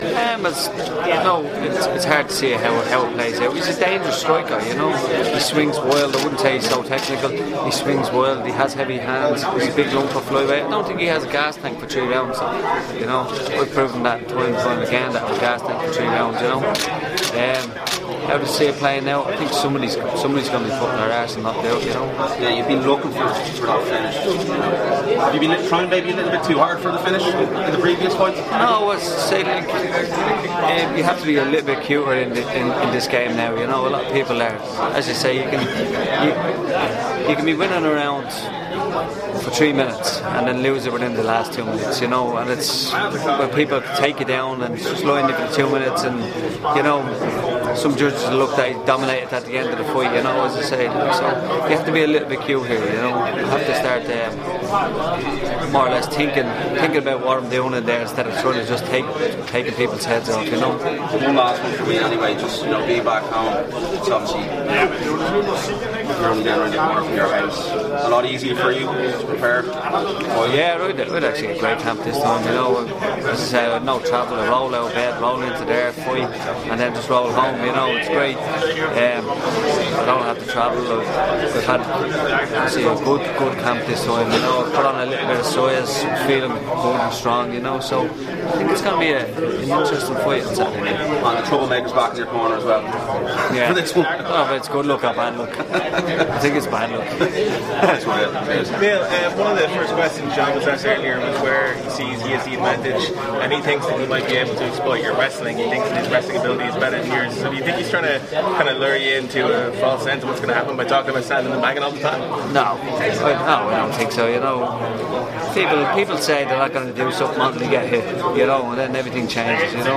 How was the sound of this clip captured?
at the UFC Dublin media day.